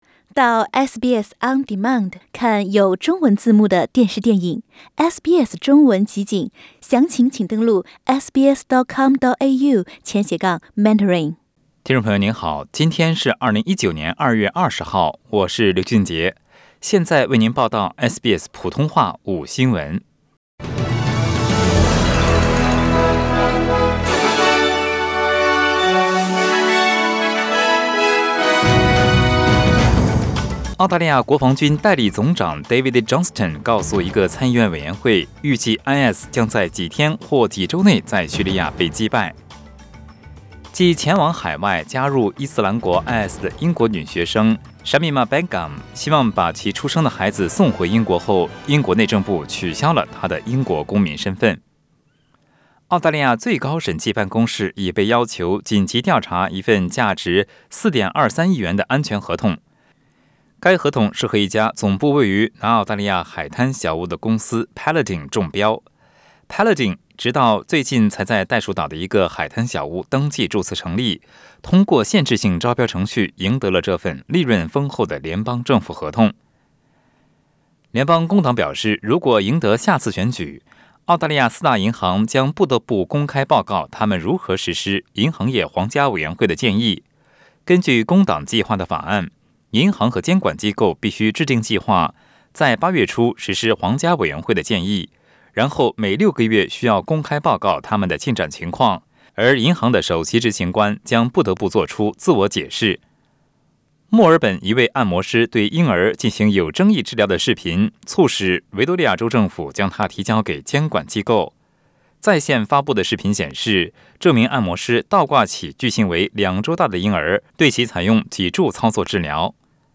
SBS午新聞 （2月20日）